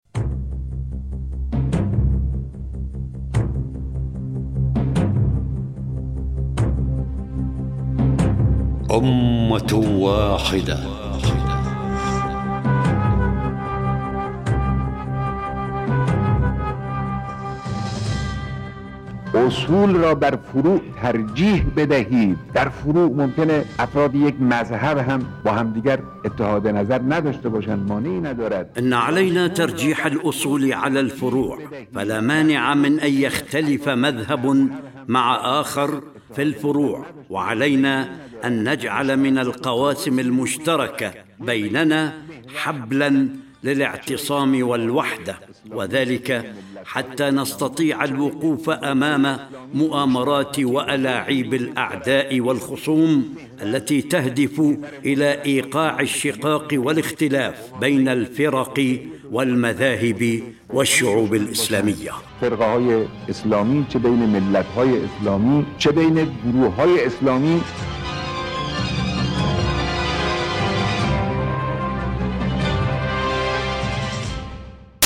إذاعة طهران- أمة واحدة: الحلقة 5- كلمات قائد الثورة الإسلامية الإمام الخامنئي حول الوحدة الإسلامية.